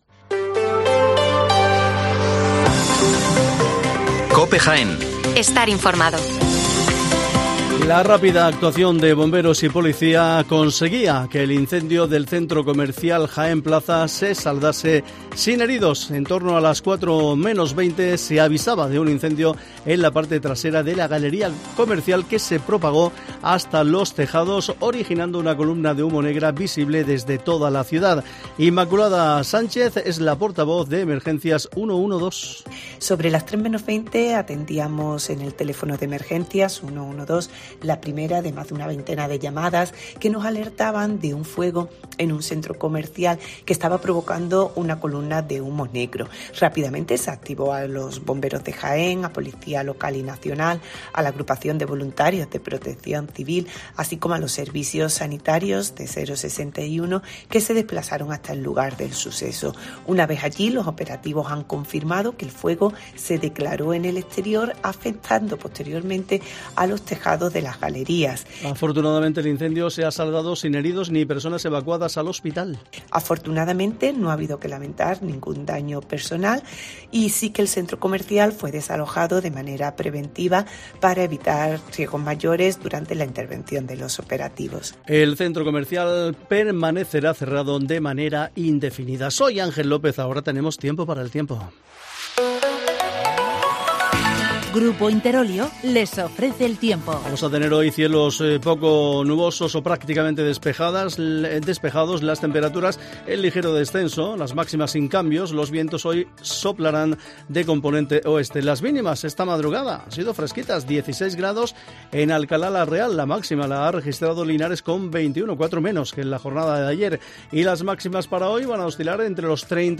Las noticias matinales en Herrera en COPE 8:24 horas